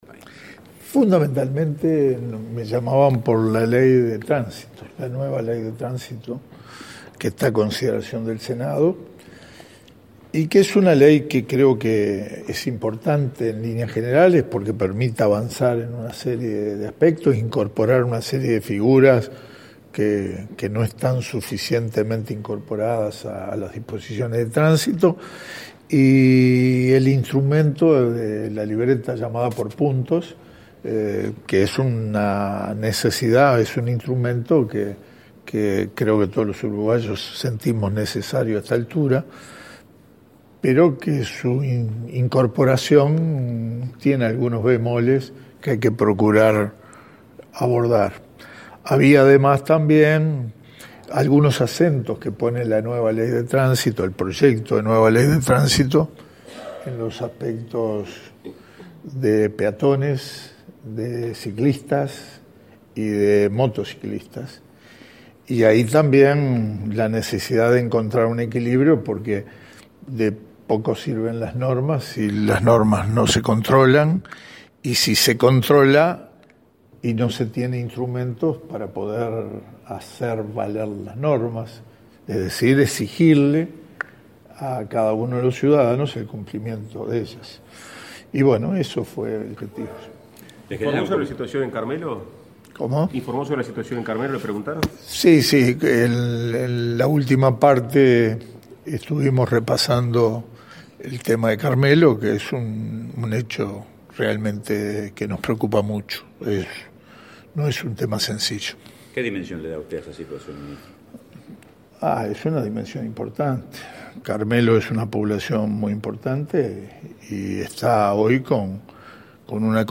Tras su comparecencia ante la Comisión de Transporte del Senado por el proyecto de ley de tránsito, el titular del área, Víctor Rossi, confirmó a la prensa que el ministerio colocará en Carmelo una balsa para mejorar la conectividad en dicha localidad, tras la rotura del puente. Dijo que este estaba en perfecto estado y que los barcos que provocaron el daño no tenían autorización para atracar en ese lugar.